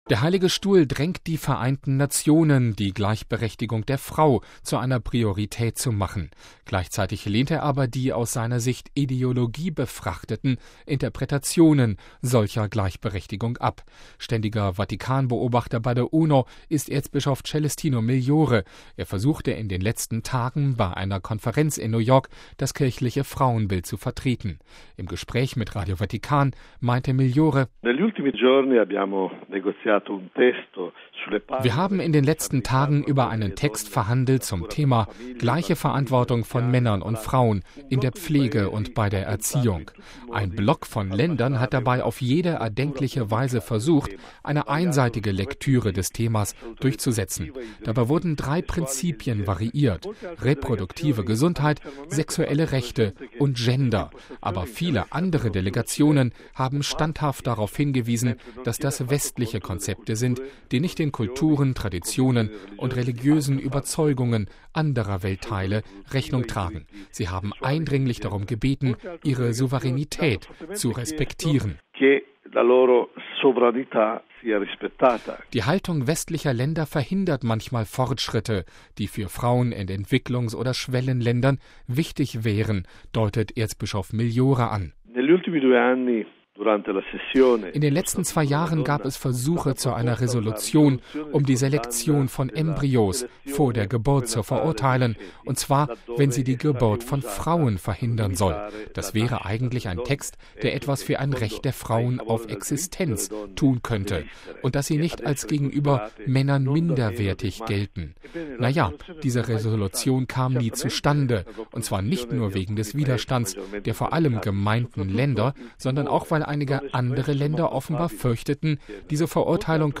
Der Ständige Vatikan-Beobachter bei der UNO, Erzbischof Celestino Migliore, versuchte in den letzten Tagen im New Yorker Glaspalast das kirchliche Frauenbild zu vertreten. Im Gespräch mit Radio Vatikan meinte Migliore, die Polemiken über „reproduktive Gesundheit“ und „Gender“ schadeten letztlich der Sache der Frauen: